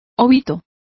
Also find out how obitos is pronounced correctly.